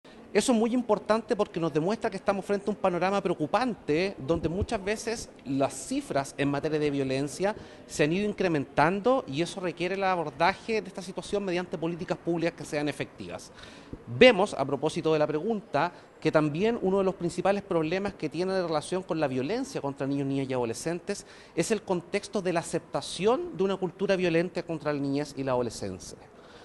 El defensor de la Niñez, Anuar Quesille, se refirió al punto del informe que da cuenta del aumento en los homicidios de niños, niñas y adolescentes.